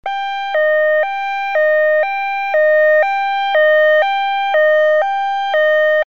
Звук сирены скорой помощи.
skoraya_pomosh.mp3